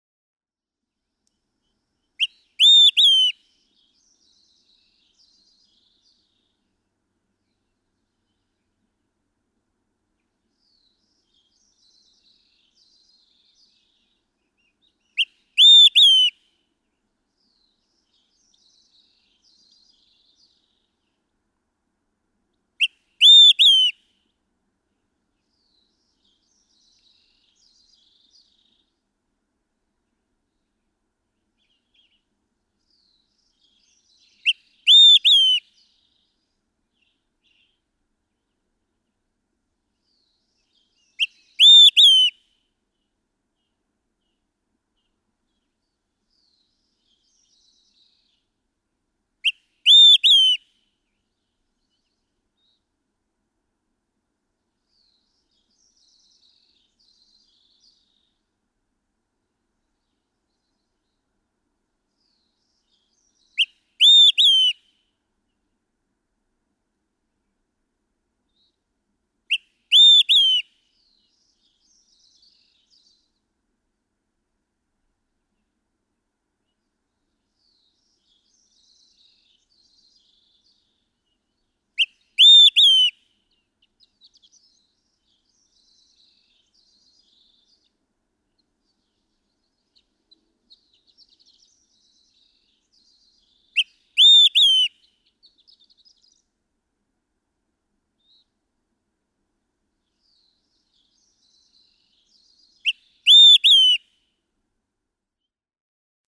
Olive-sided flycatcher
Note the far slower pace of singing during this mid-morning effort.
Mt. Princeton, Colorado.
380_Olive-sided_Flycatcher.mp3